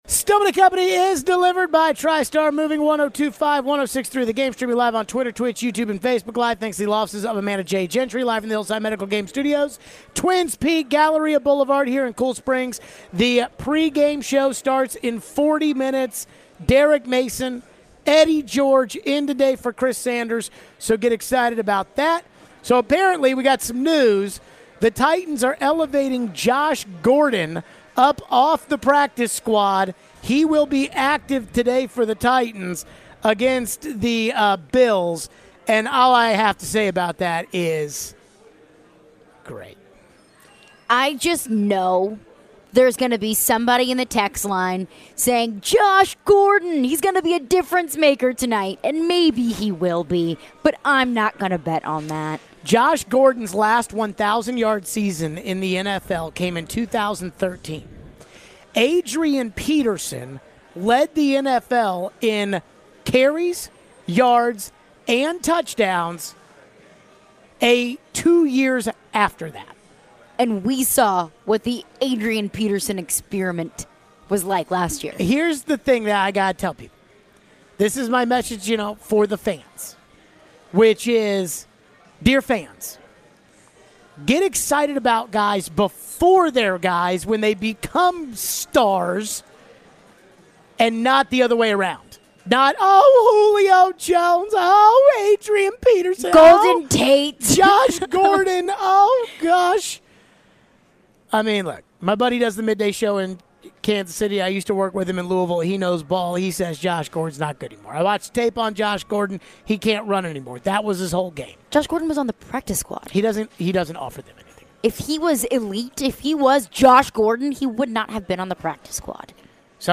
We go to the phones. How would we feel about the Titans if they won tonight?